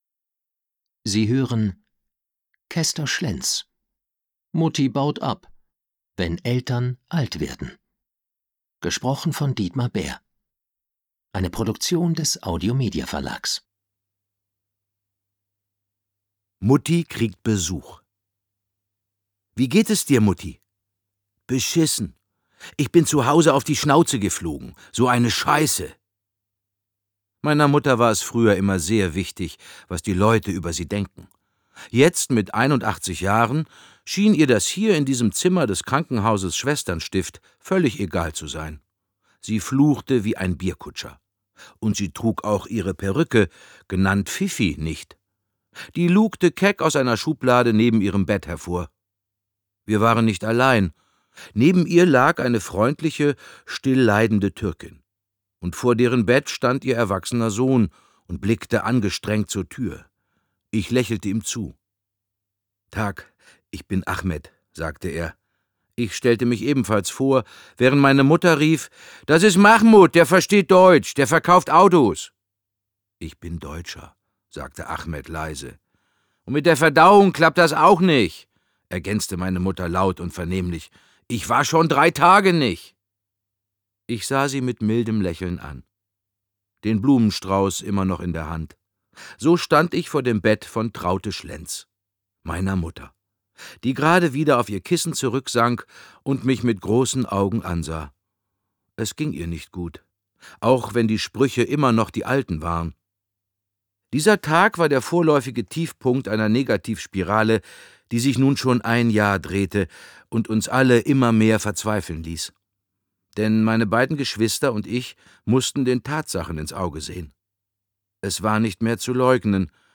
Mitarbeit Sprecher: Dietmar Bär